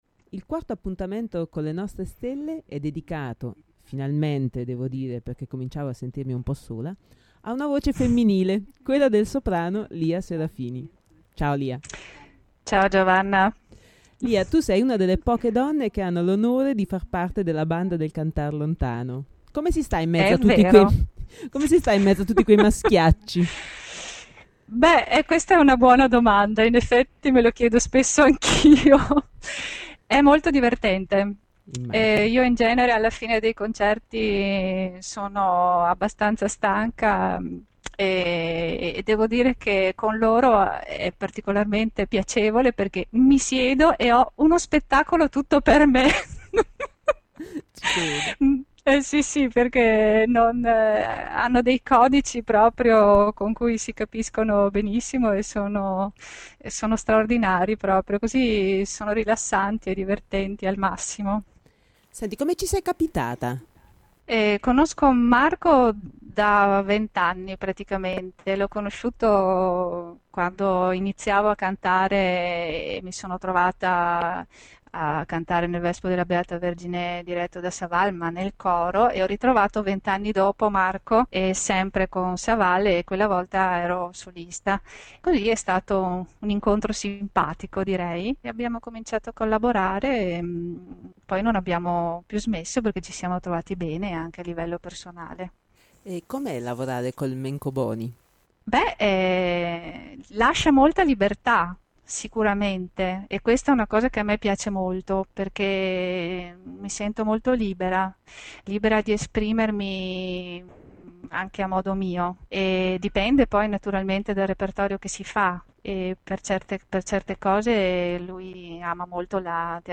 Finalmente una presenza femminile nel panorama stellato del Cantar Lontano: il soprano